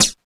27 FLNGE HAT.wav